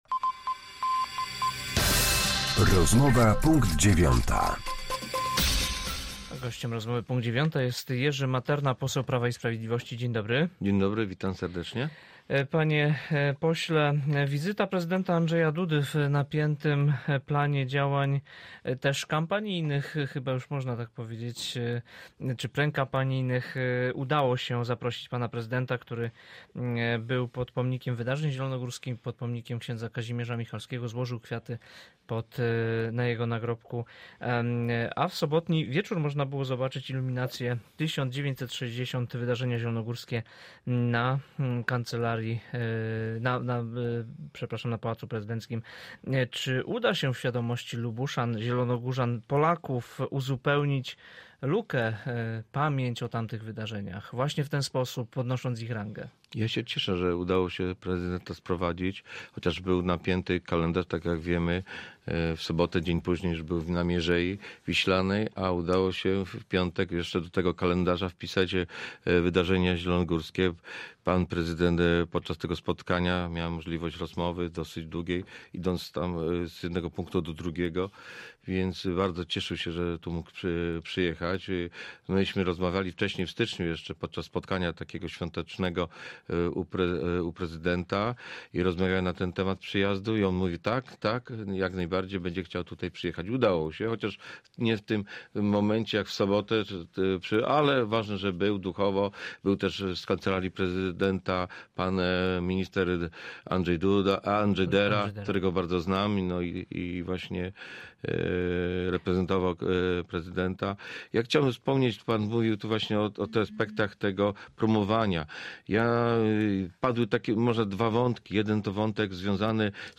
Z posłem Prawa i Sprawiedliwości rozmawia